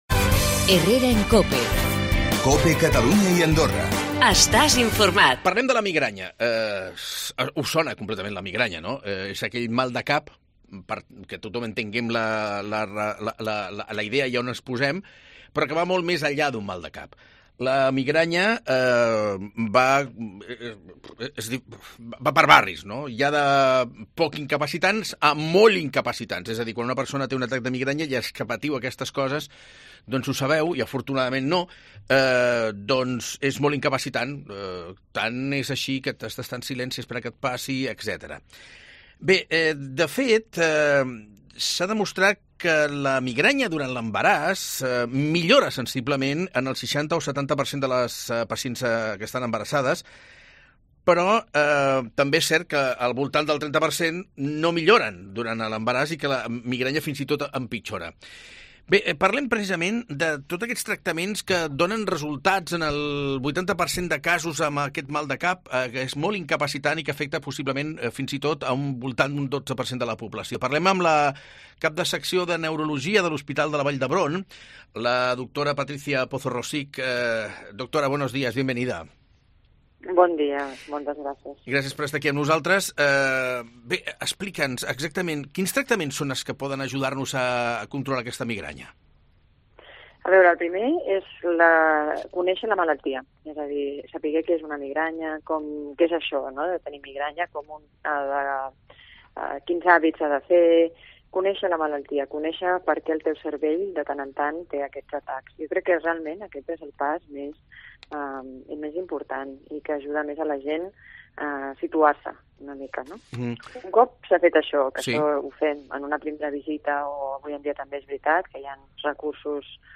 hemos entrevistado